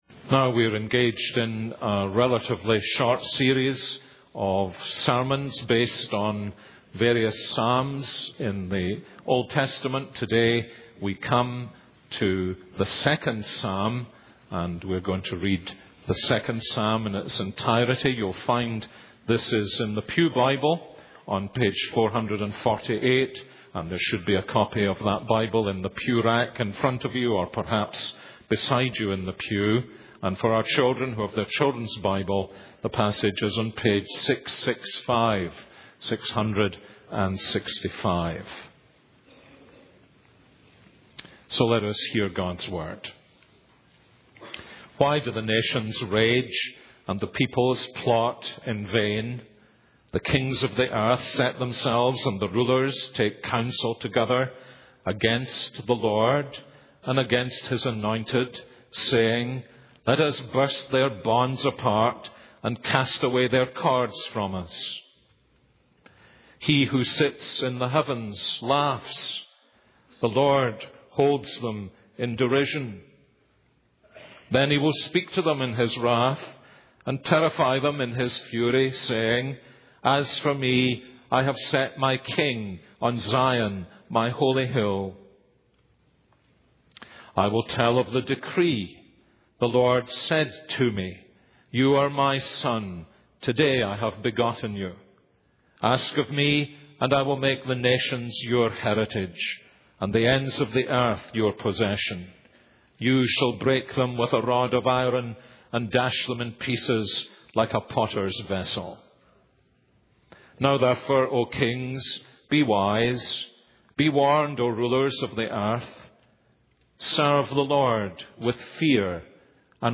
This is a sermon on Psalm 2:1-12.